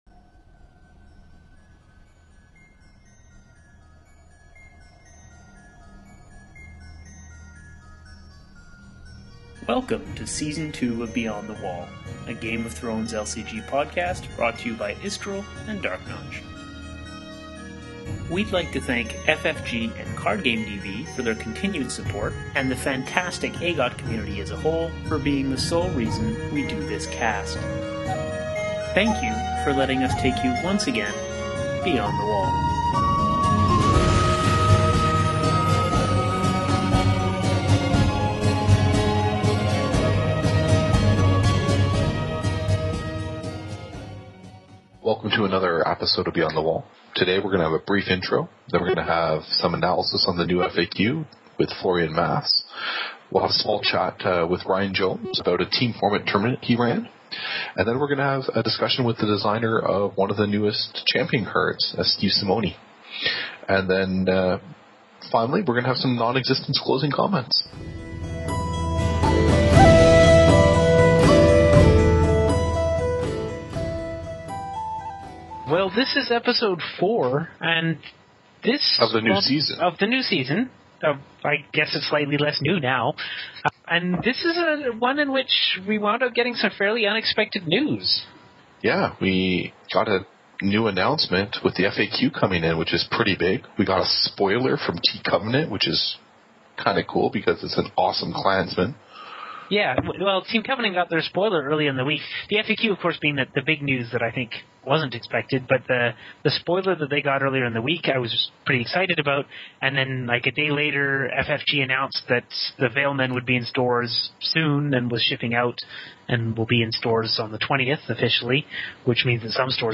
Mostly by cutting out all the good parts - the editing is a touch rougher here than usual (sorry!).